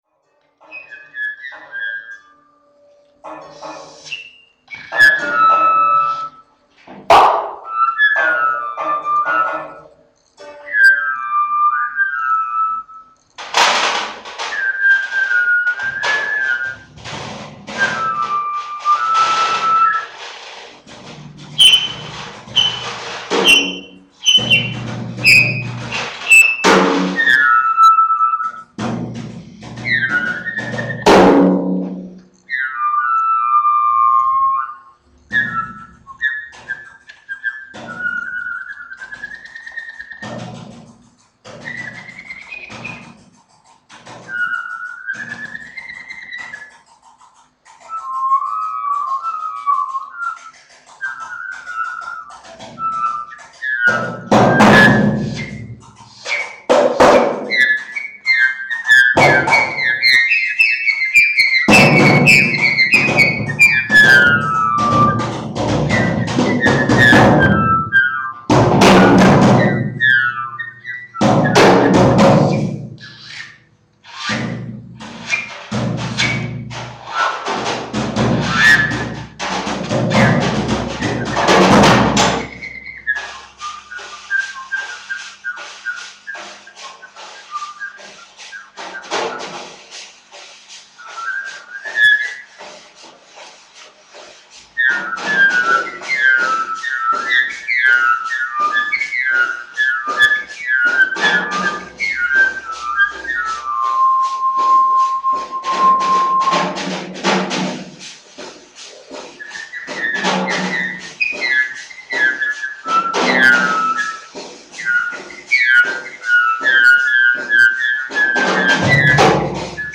free-jazz cdr
Another minimal jazzy work